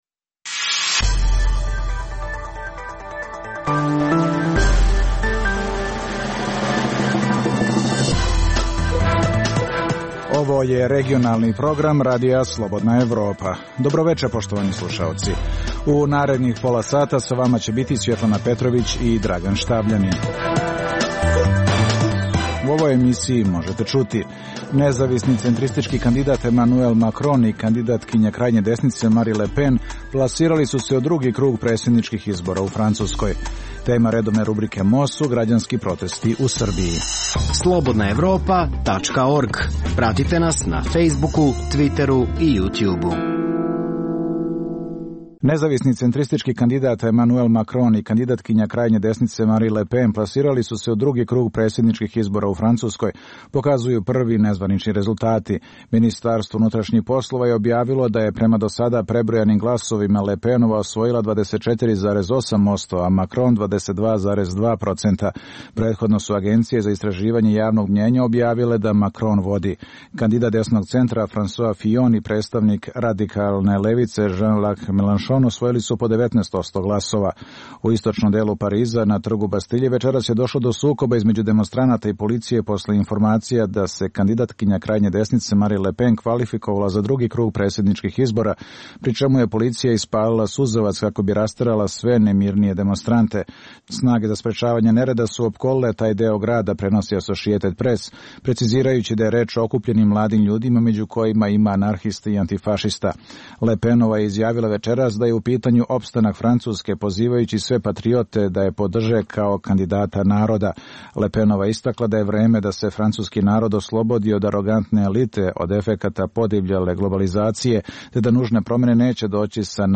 u kojem ugledni sagovornici iz regiona razmtraju aktuelne teme. Drugi dio emisije čini program "Pred licem pravde" o suđenjima za ratne zločine na prostoru bivše Jugoslavije.